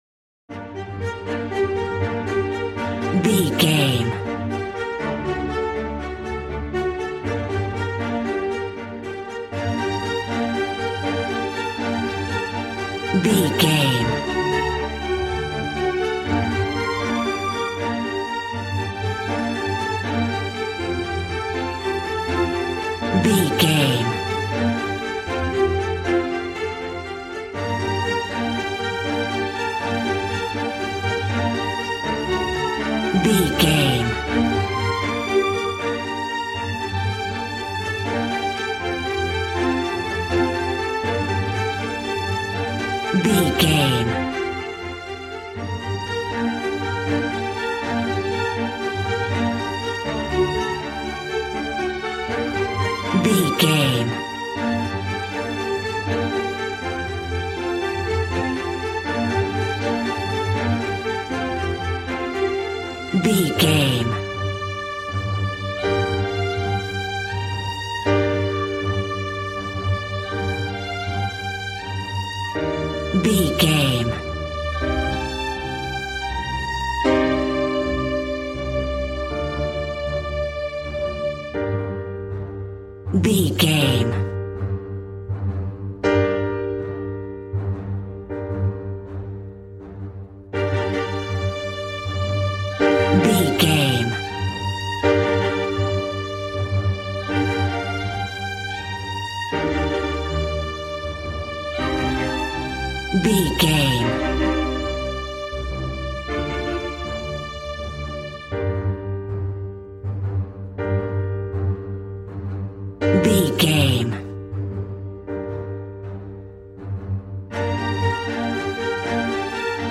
Regal and romantic, a classy piece of classical music.
Aeolian/Minor
cello
violin
strings